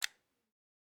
FlashlightOpen.mp3